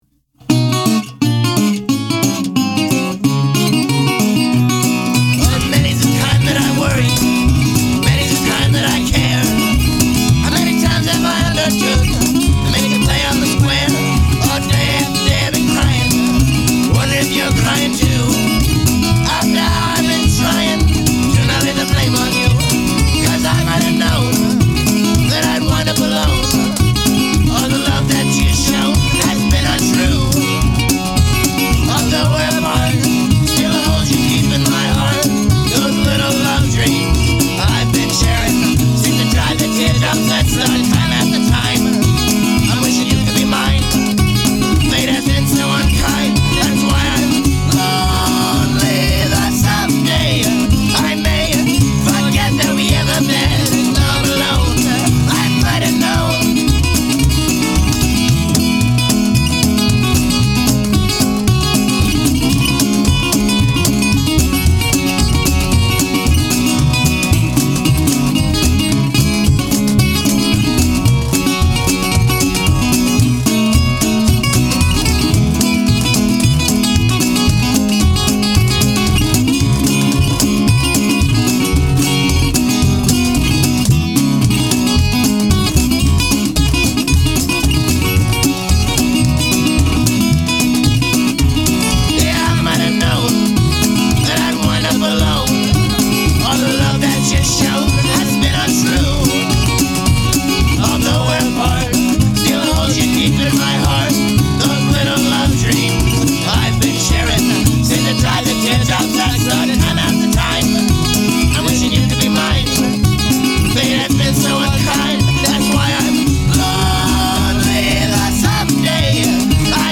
A ragtime gallimaufry from Eugene, Oregon U$A